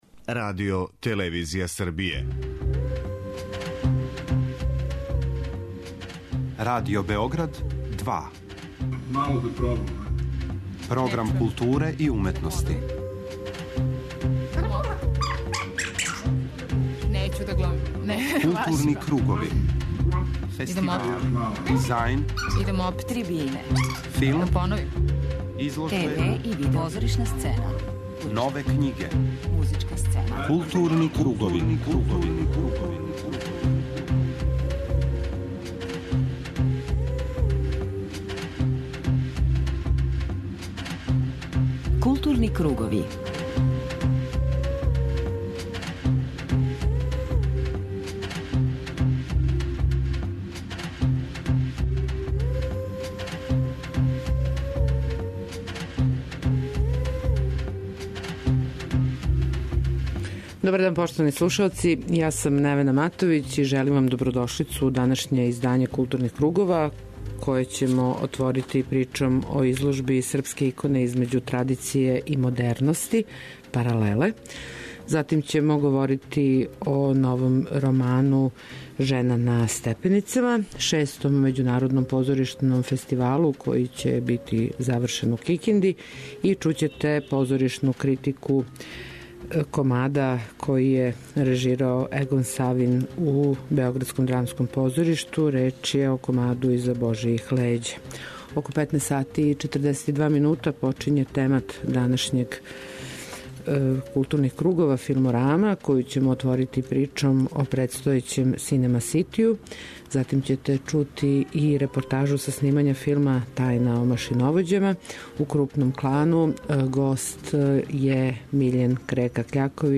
У рубрици Крупни план чућете разговор са сценографом Миљеном Креком Кљаковићем, овогодишњим добитником Златног печата Југословенске кинотеке и добитником најпрестижнијих светских и европских награда, међу којима су Цезар, Феликс, Еми, Златна арена у Пули...
преузми : 53.14 MB Културни кругови Autor: Група аутора Централна културно-уметничка емисија Радио Београда 2.